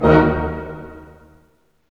Index of /90_sSampleCDs/Roland LCDP08 Symphony Orchestra/HIT_Dynamic Orch/HIT_Orch Hit Min
HIT ORCHM06L.wav